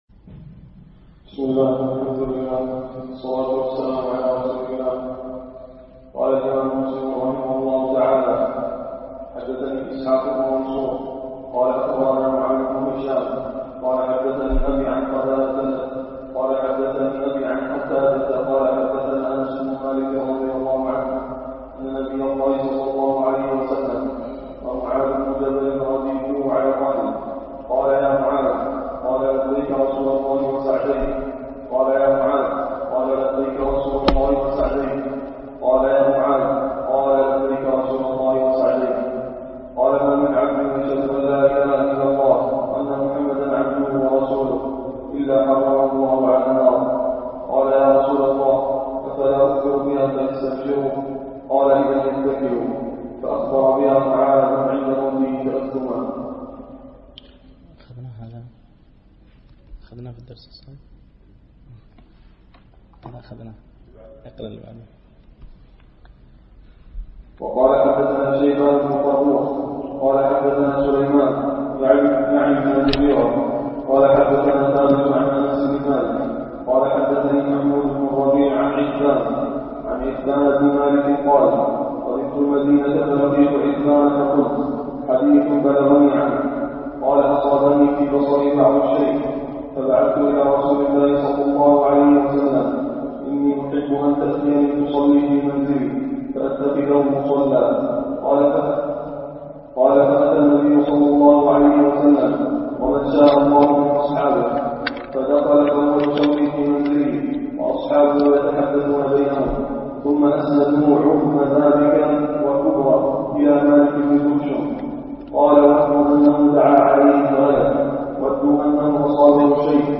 دروس مسجد عائشة
MP3 Mono 22kHz 32Kbps (VBR)